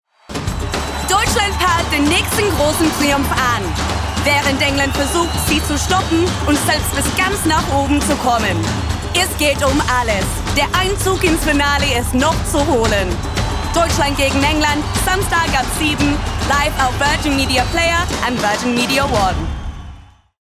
Young, Fresh with Warm Husky Tones
Promo, Cool, Energetic, Confident